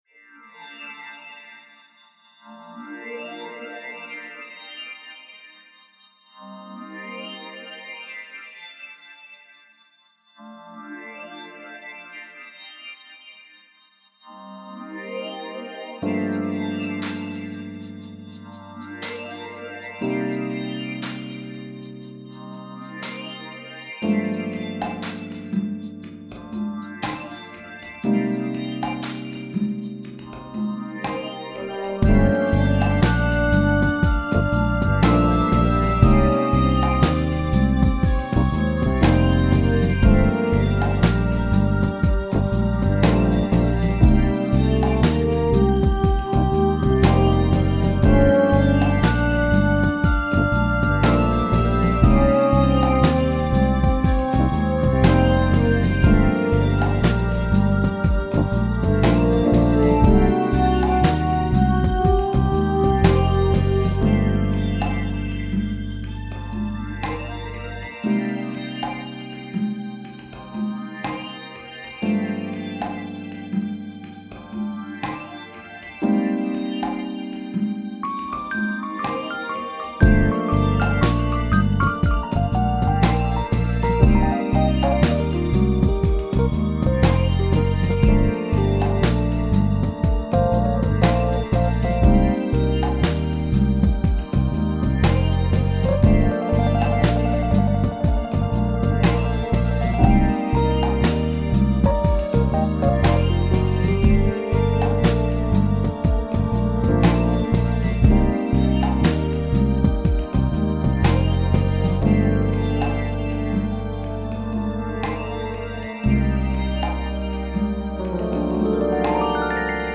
Spy music